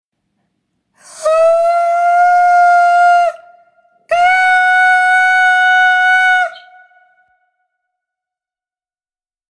der klang ist etwas leiser, man hört wie luft mit kommt. als schlechtes beispiel: - erst mit geringem stimmbandschluß - danach eventuell etwas besser F5 bzw. F#5 Anhänge Stimmbandschluß.mp3 222,9 KB · Aufrufe: 2.442